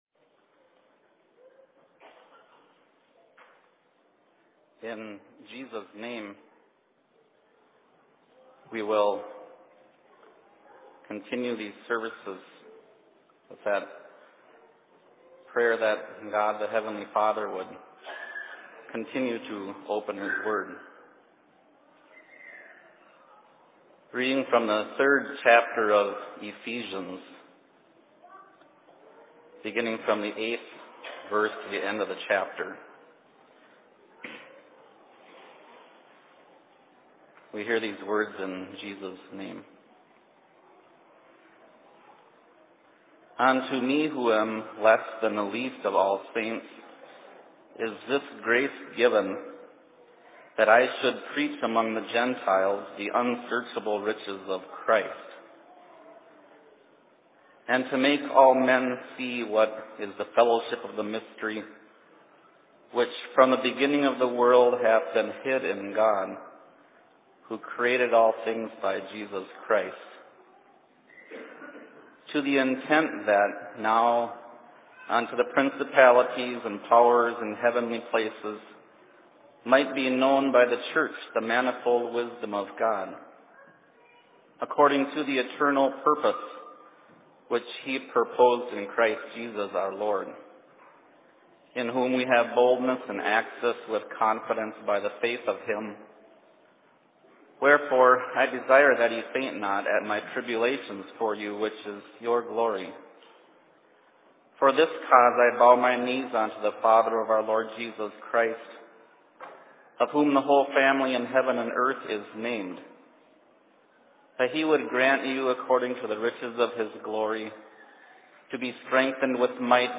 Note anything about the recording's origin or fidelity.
Location: LLC Cokato